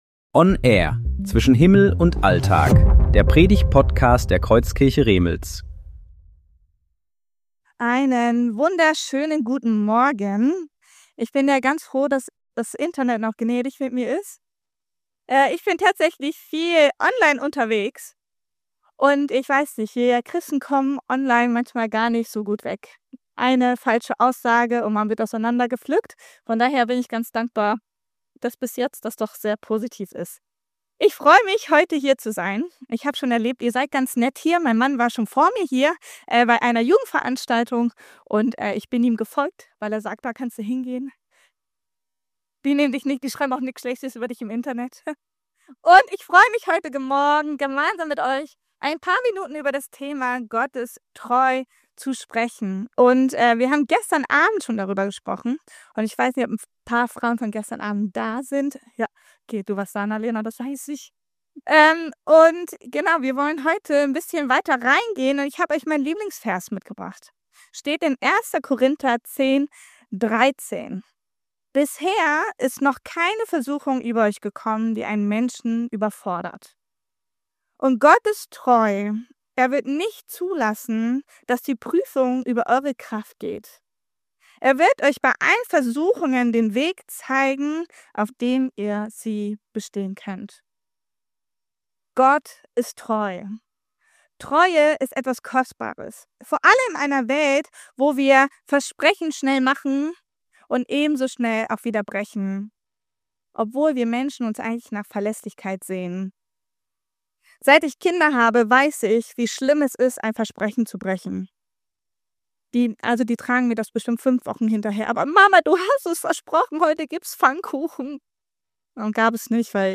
Predigten
Gottesdienst Datum: 18.01.2026 Bibelstelle: 1. Korinther 10,13 Audio herunterladen